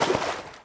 splash.ogg